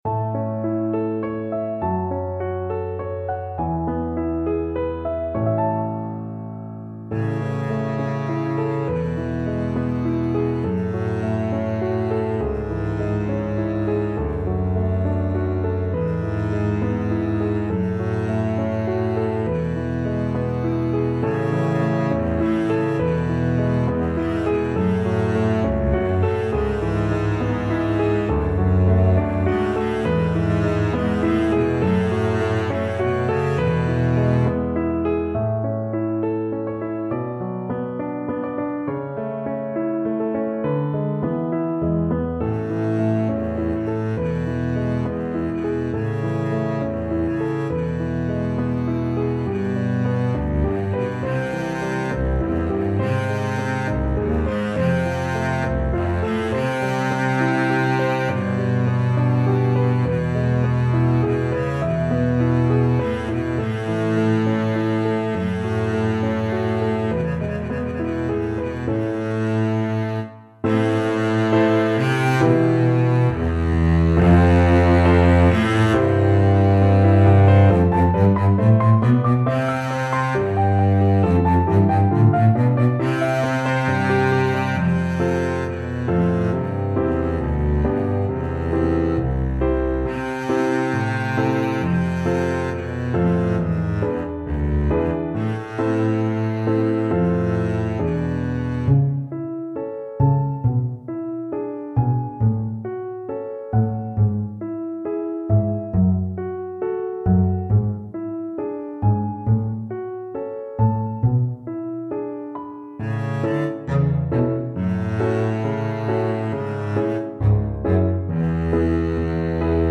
Contrebasse et Piano